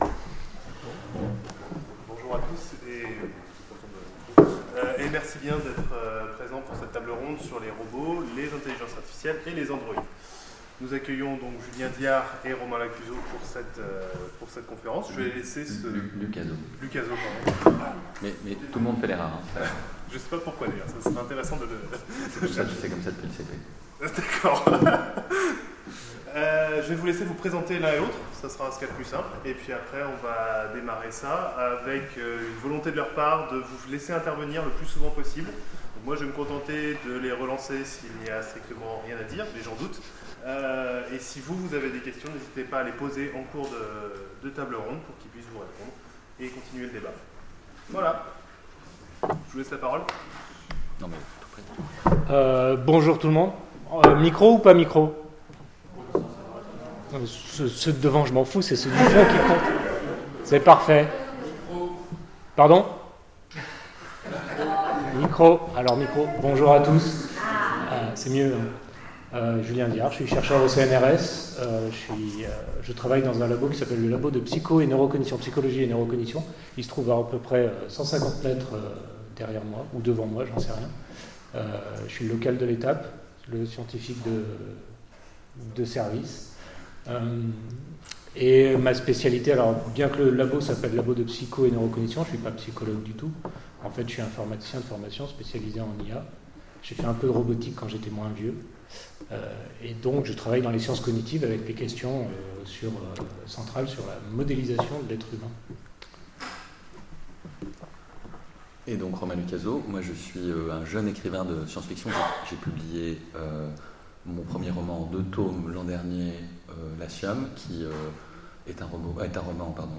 Convention SF 2017 : conférence Androïdes, robots et autres I.A
Convention_sf_2017_conference_Androides_robots_et_autres_IA_ok.mp3